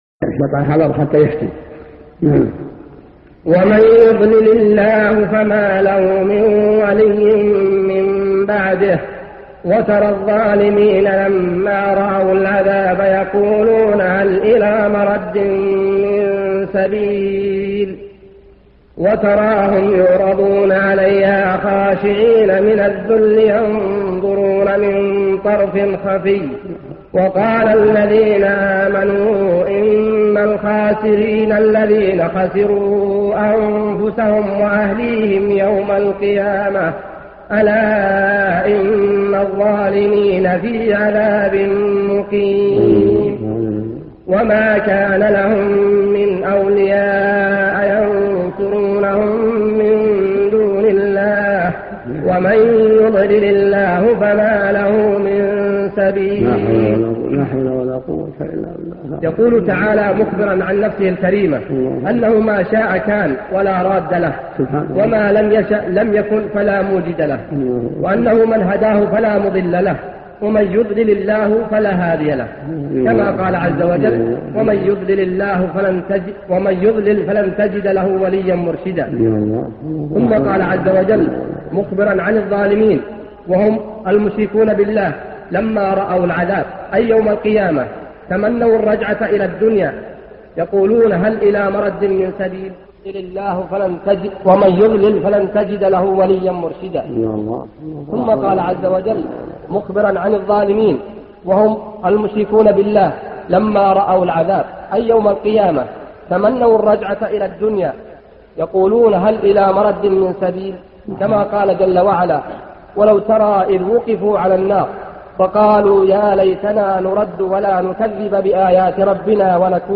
[Added some audio files to this article where the Shaikh is crying, here he was crying over hearing some verses from Surah Shooraa during one of his lessons]
crying-over-verses-in-surah-shooraa.mp3